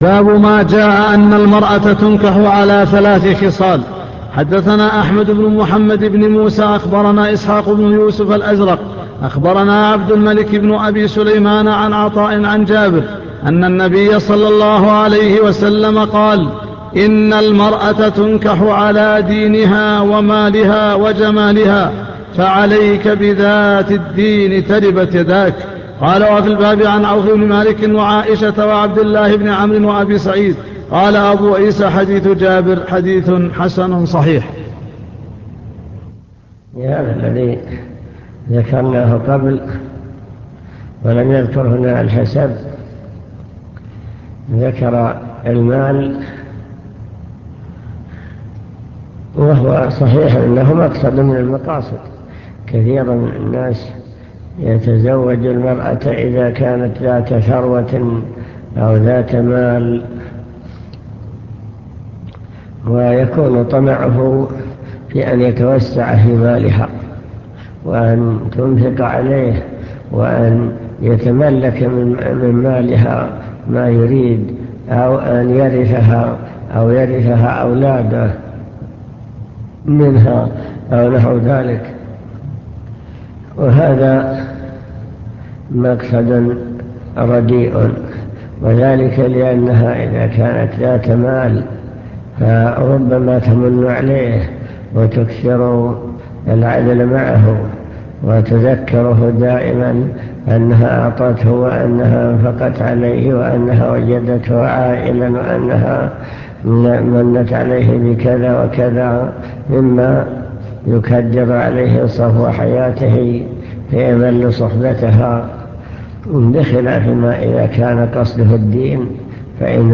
المكتبة الصوتية  تسجيلات - كتب  شرح سنن الترمذي كتاب النكاح